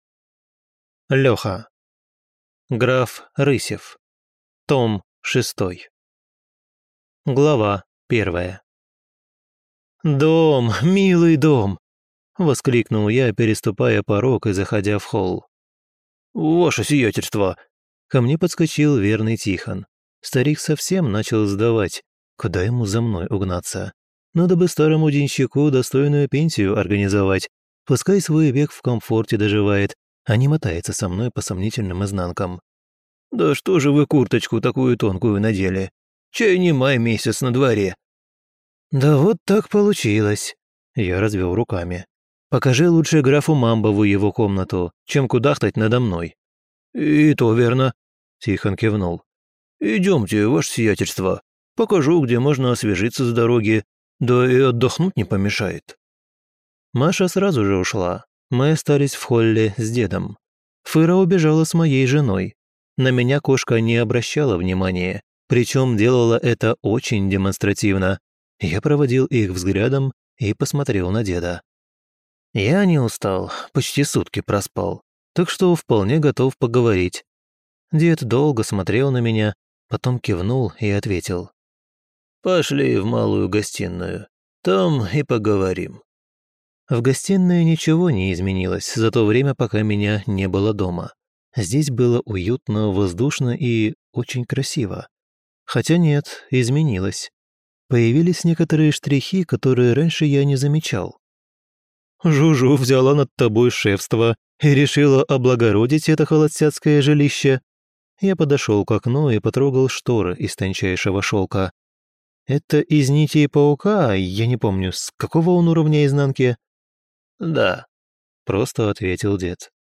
Аудиокнига «Граф Рысев – 6».